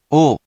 We’re going to show you the character, then you you can click the play button to hear QUIZBO™ sound it out for you.
In romaji, 「を」 is transliterated as 「wo」which sounds sort of like 「whoa」, but when used as a particle, sounds like o